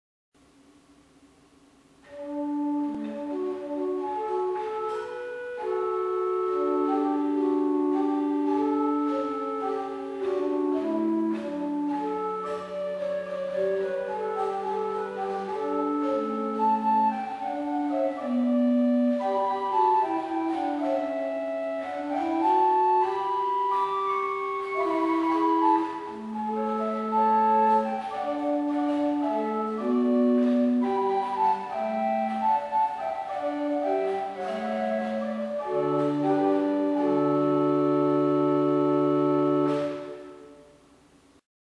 The sound, in fact, is very similar to a Tannenberg organ.
The Floet 8' is open wood.
Listen to an improvisation on the Floet 8' by clicking